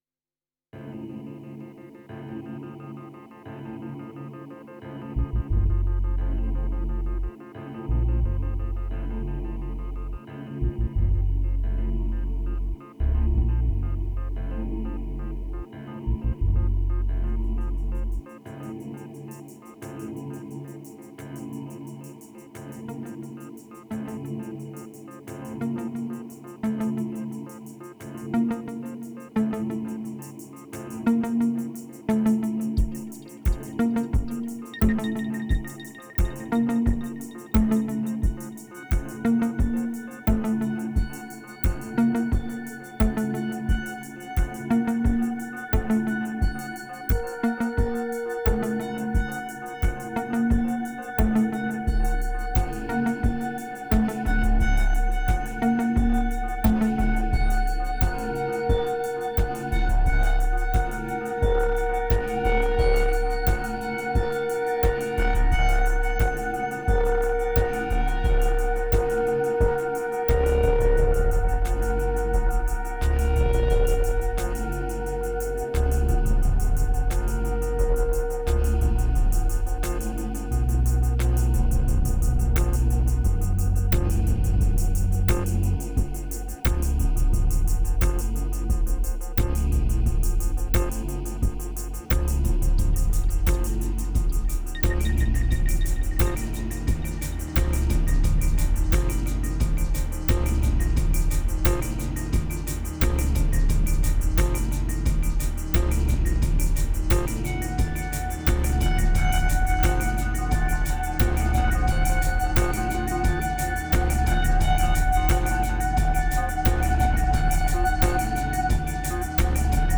2409📈 - 31%🤔 - 88BPM🔊 - 2017-06-03📅 - -80🌟